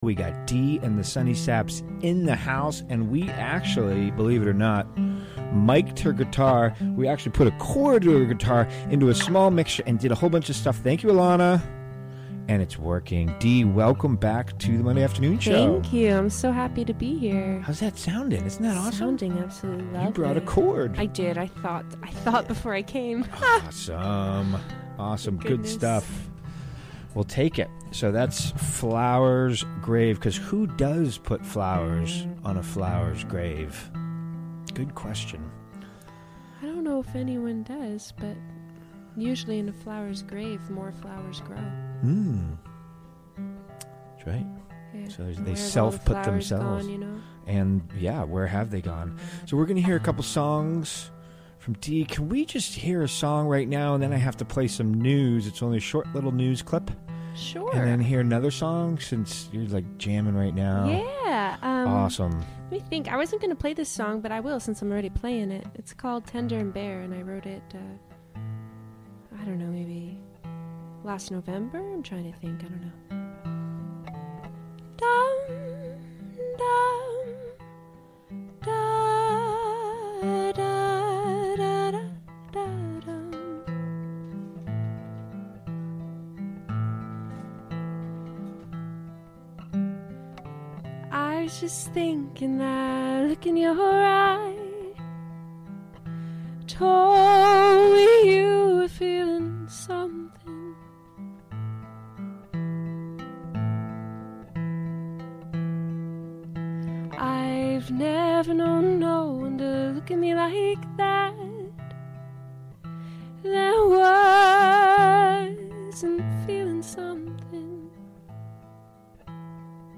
Recorded during the WGXC Afternoon Show Monday, January 22, 2018.